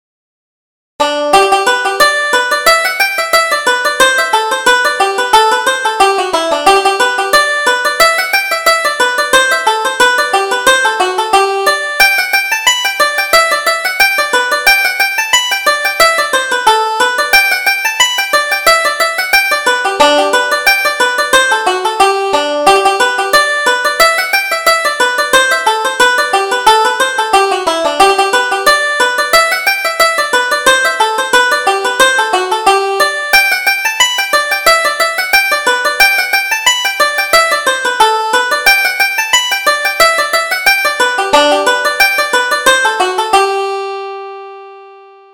Reel: Fair and Forty